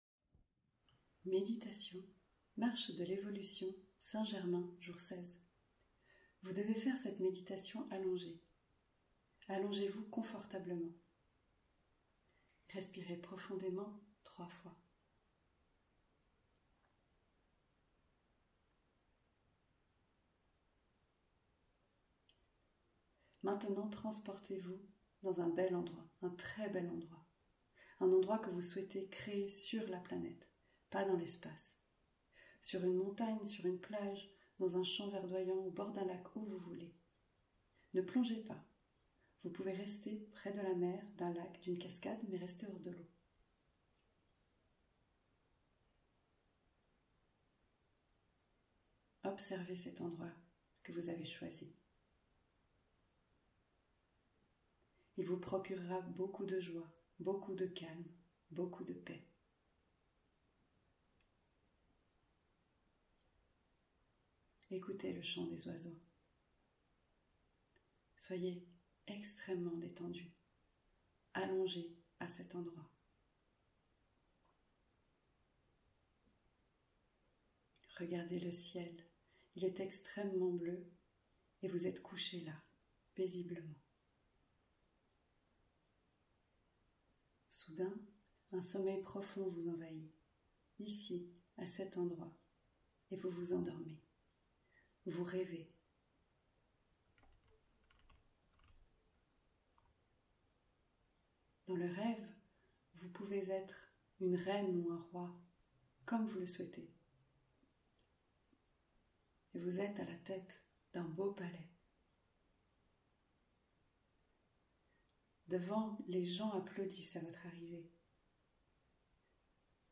Méditation - sans_pub